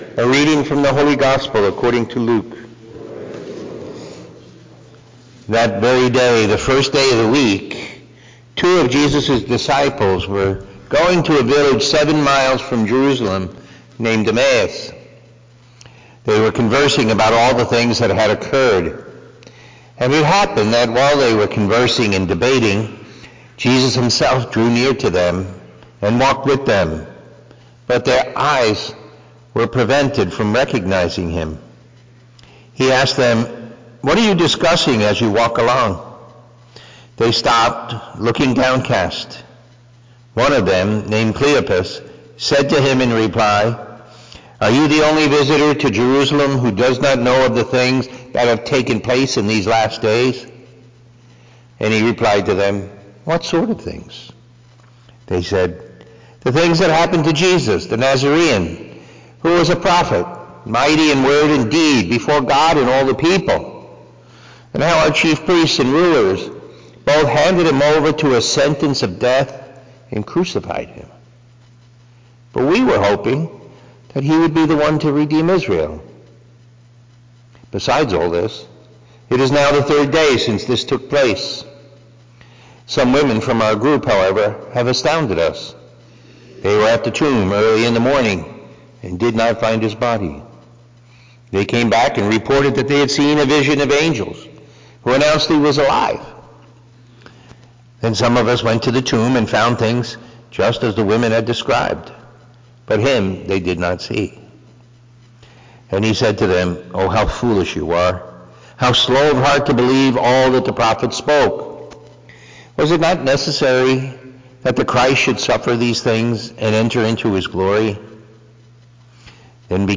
Looking to go deeper in your prayer life? Listen to the homily from the Sunday Mass and meditate on the Word of God.